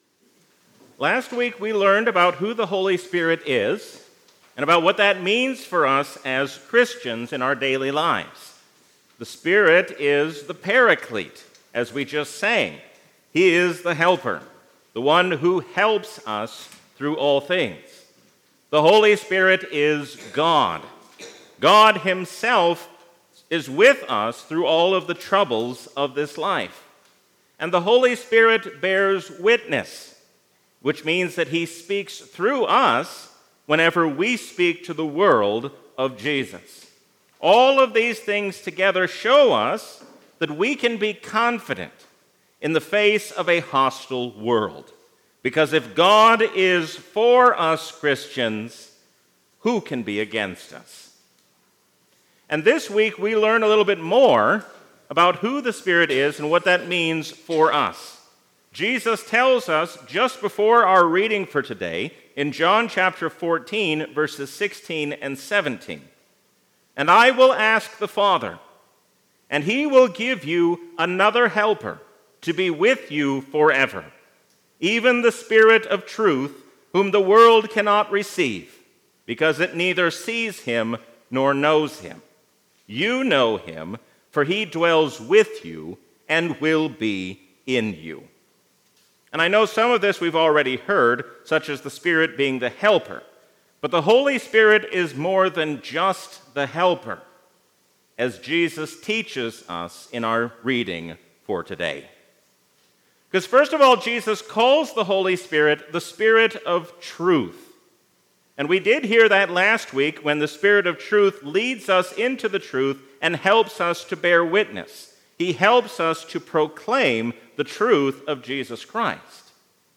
A sermon from the season "Pentecost 2023."